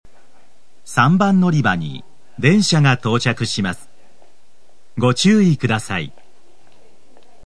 ＜スピーカー＞　天井埋込型
＜曲名（本サイト概要）＞　西鉄新主要　／　全線-男性放送
○接近放送 接近放送は、時分・種別を言わない簡易的なものです。